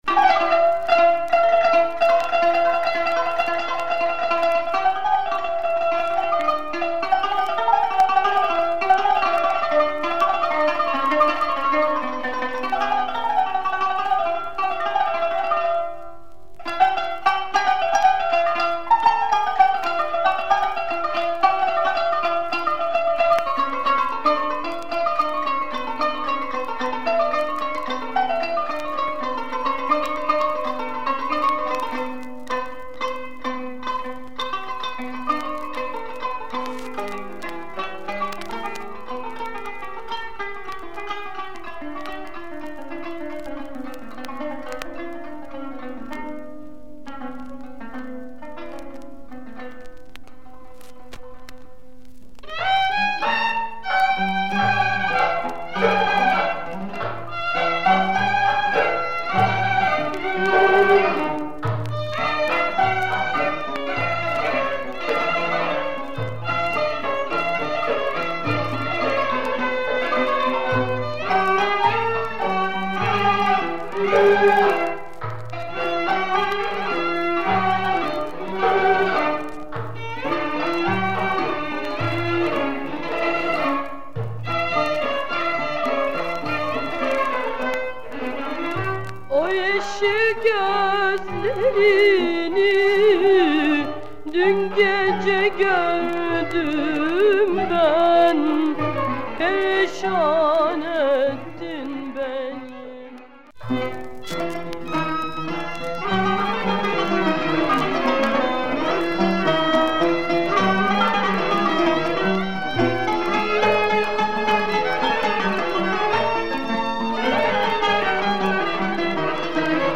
Rare Turkish music 7'
female singer